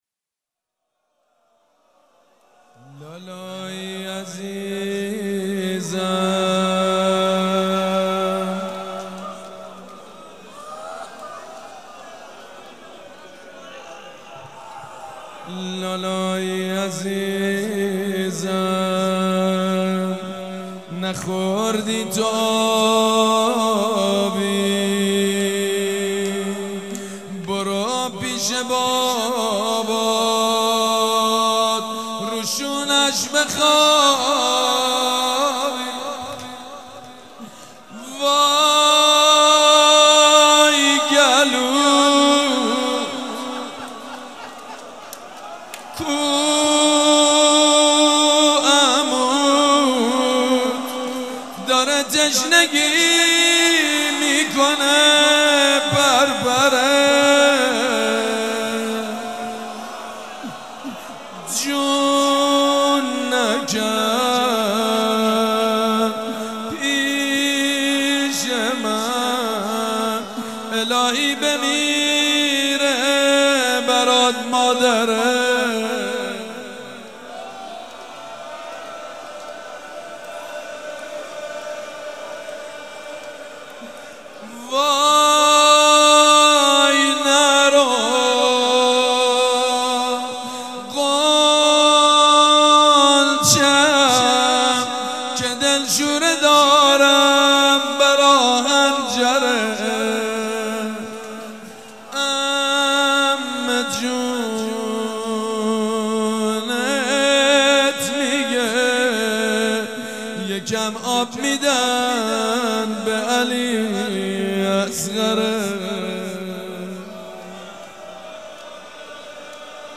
روضه
مراسم عزاداری شب هفتم